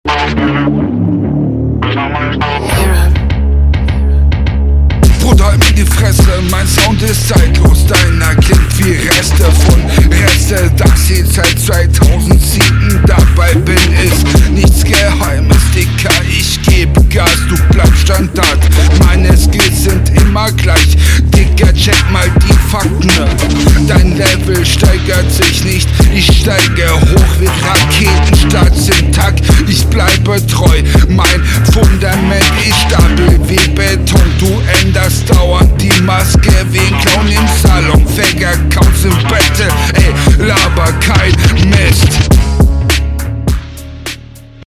Flow schwach, Mix grausam, Punches nicht da, Reime schrecklich
Du hast Druck in der Stimme, daraus lässt sich auf jeden Fall was machen.